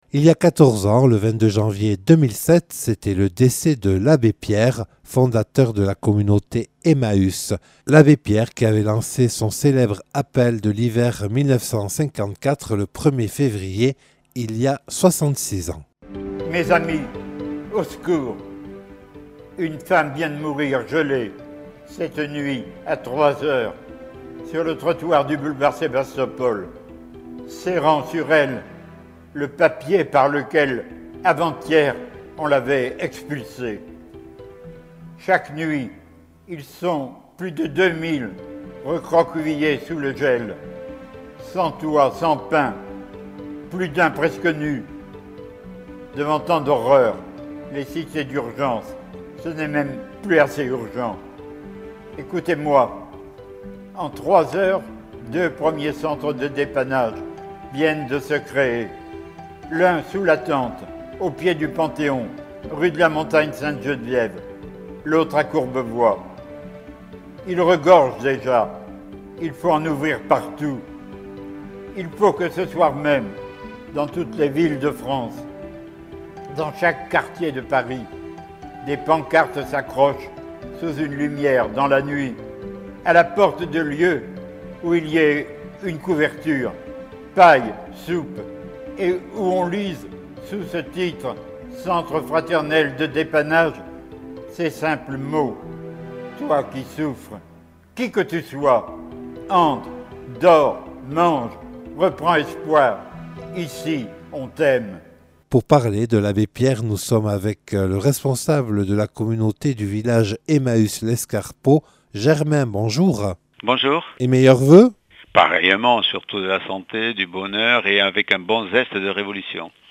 Accueil \ Emissions \ Infos \ Interviews et reportages \ 14ème anniversaire du décès de l’Abbé Pierre : osons un monde plus juste (...)